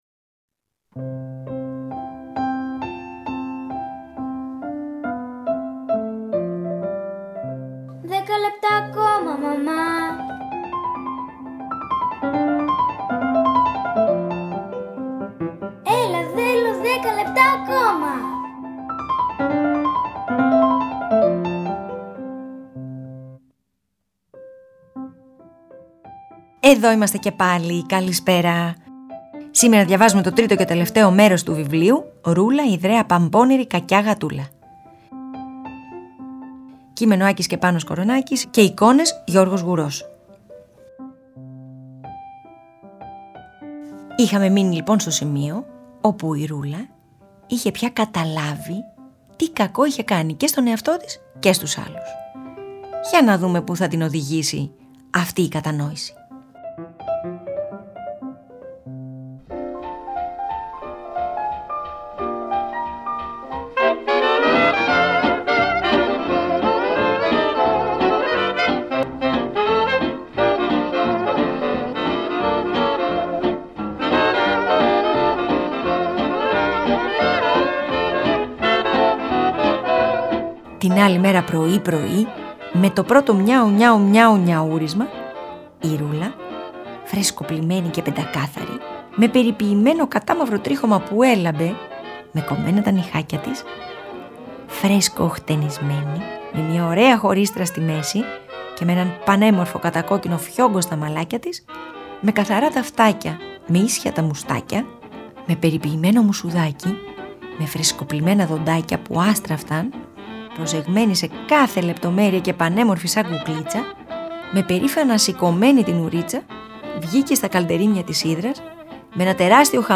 Αφήγηση-Μουσικές επιλογές